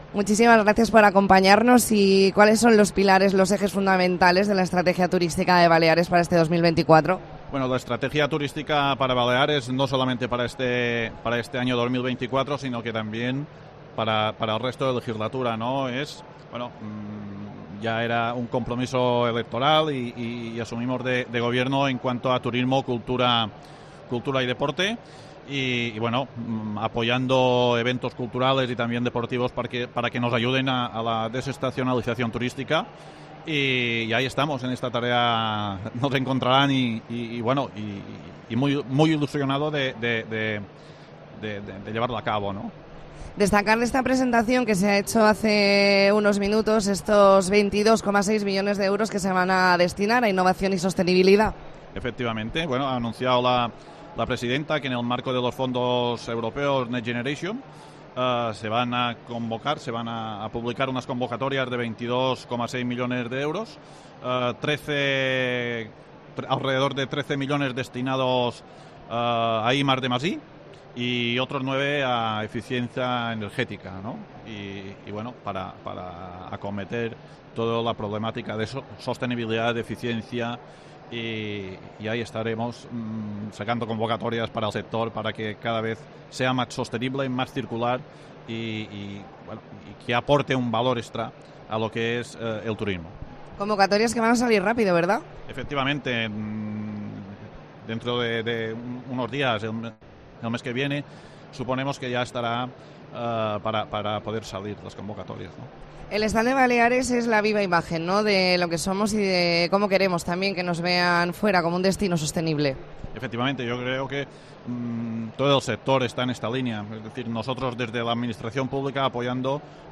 Especial COPE Baleares desde FITUR